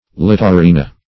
Littorina \Lit"to*ri"na\, n. [NL.